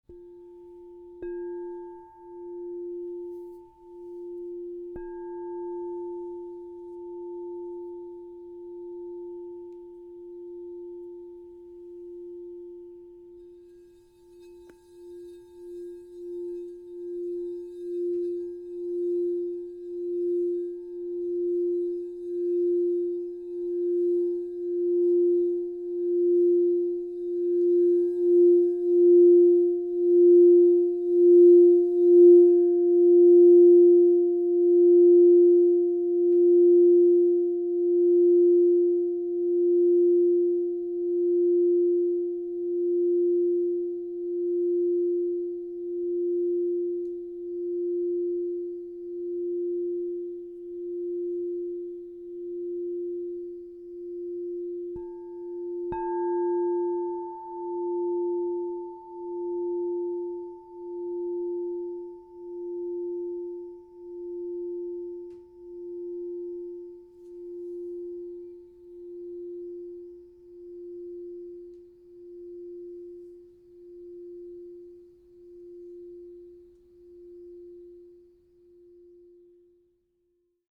Sacred Feminine Kudaka Island Salt, Pink Aura Gold, Lemon Aura Gold (Base) 8" F +5 Crystal Tones singing bowl
The 8-inch size delivers focused, resonant tones, making it ideal for personal meditation, sound therapy, and enhancing sacred spaces.
Experience this 8″ Crystal Tones® True Tone triple alchemy singing bowl made with Sacred Feminine Kudaka Island Triple Alchemy in the key of F +5 .
440Hz (TrueTone), 528Hz (+)